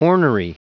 Prononciation du mot ornery en anglais (fichier audio)
Prononciation du mot : ornery